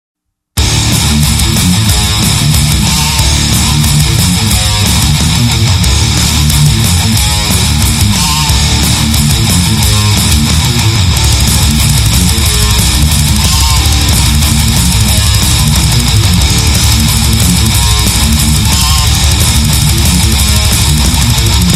• Качество: 128, Stereo
громкие
жесткие
Драйвовые
без слов
электрогитара
Death Metal
black metal
Рок рингтон